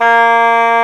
Index of /90_sSampleCDs/Roland L-CDX-03 Disk 1/WND_Bassoons/WND_Bassoon 3
WND C3  DB.wav